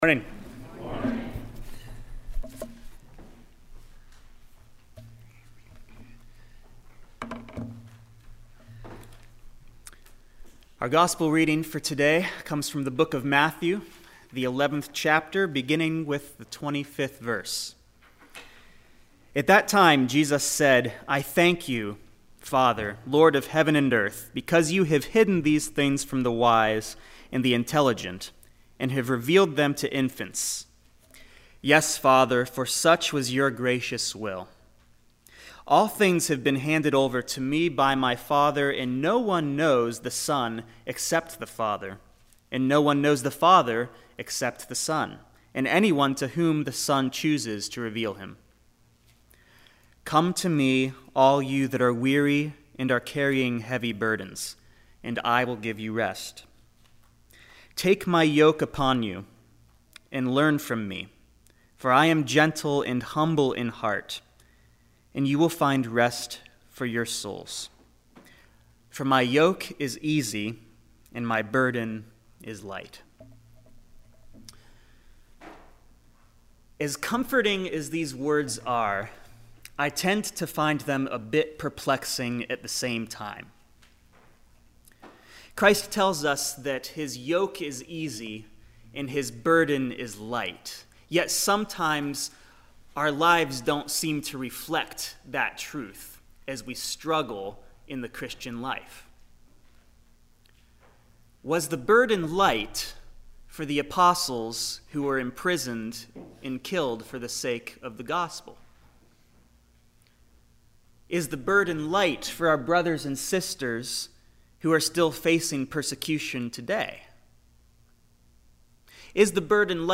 This weeks scripture and sermon:
7-6-14-scripture-and-sermon.mp3